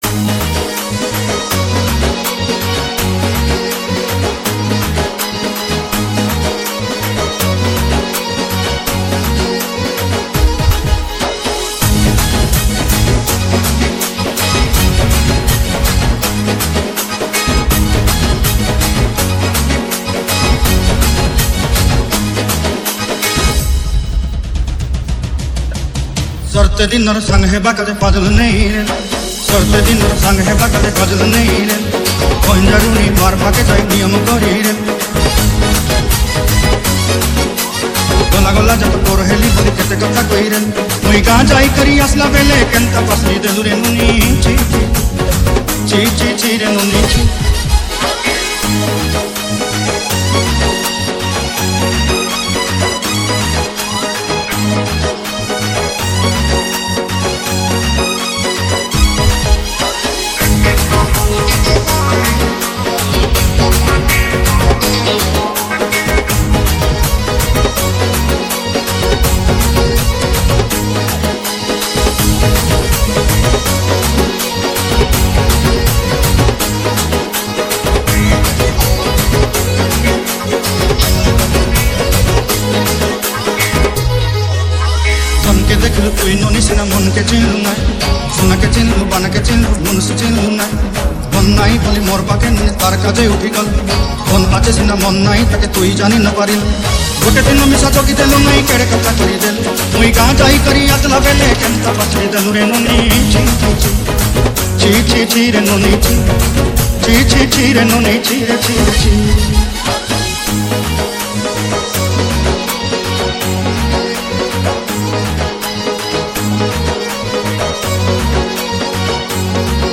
ODIA ROMANTIC DJ REMIX